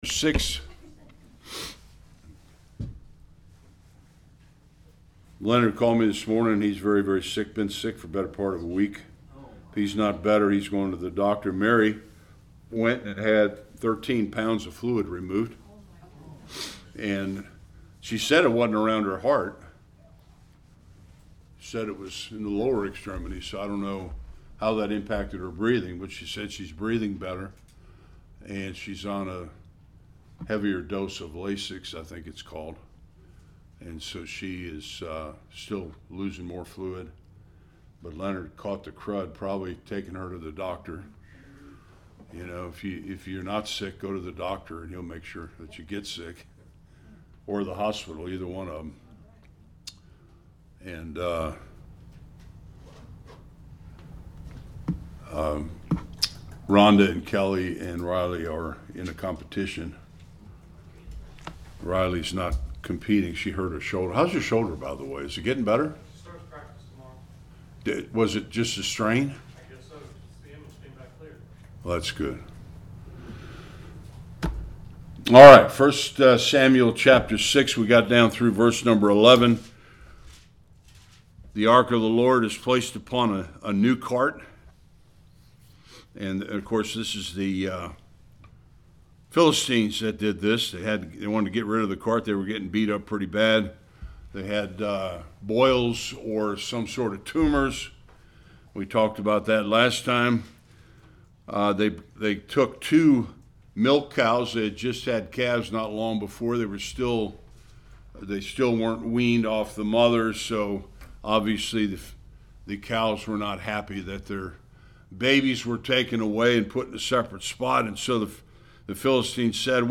1 Samuel Service Type: Sunday School The Ark of the Covenant is moved by the Philistines to Kiriath- Jearim.